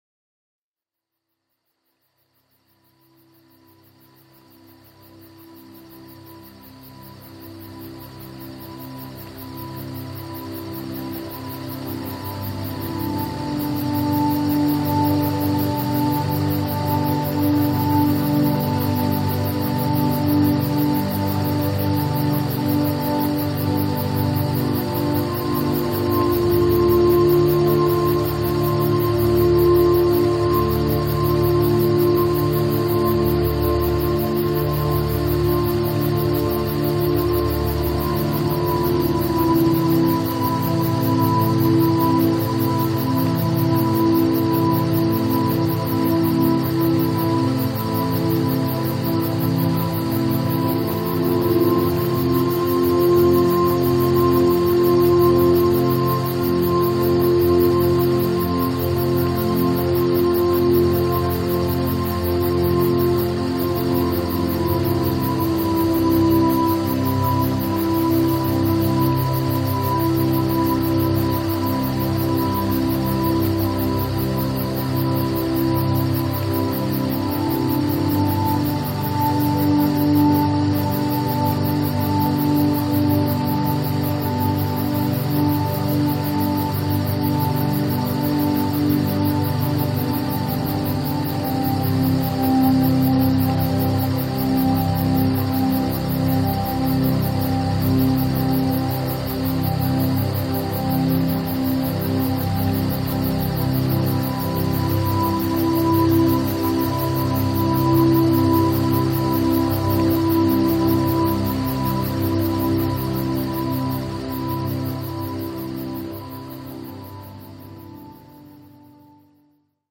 gent_dreams_eve_forest-2m.mp3